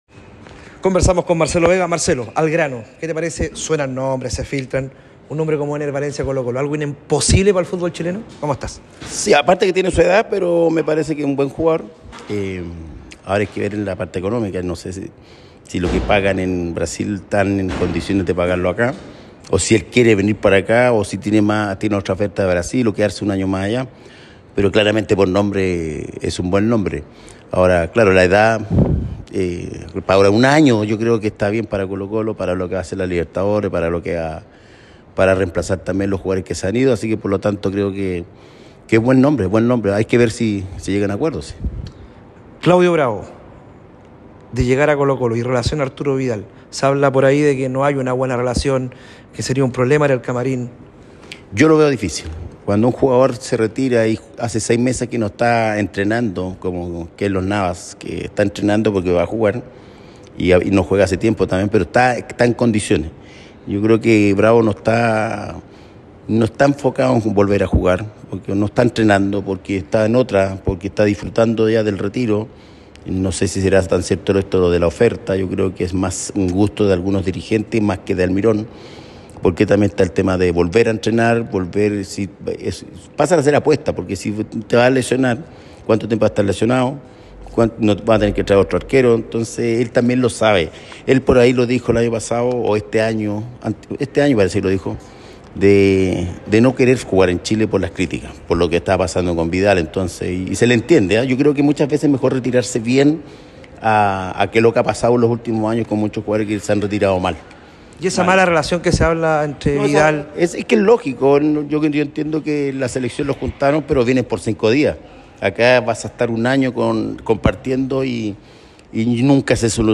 En conversación con ADN Deportes